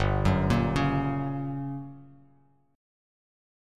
GmM7b5 chord